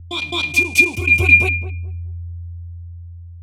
Techno / Voice / VOICEFX222_TEKNO_140_X_SC2(R).wav